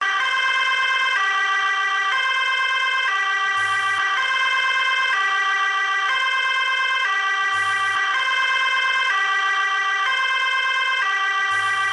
警报器 " 瑞典警察
描述：来自瑞典紧急车的警报器